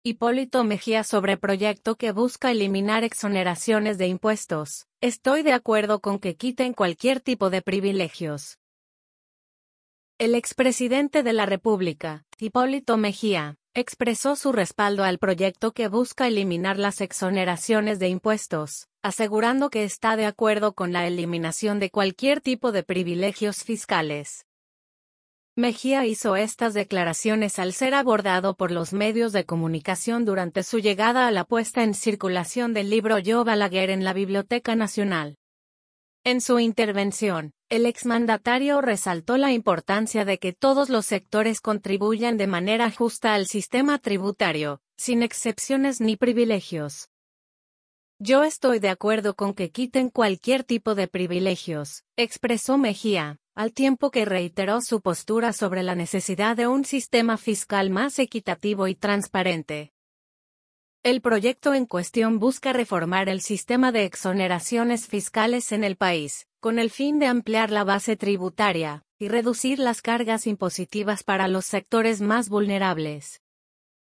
Mejía hizo estas declaraciones al ser abordado por los medios de comunicación durante su llegada a la puesta en circulación del libro “Yo Balaguer” en la Biblioteca Nacional.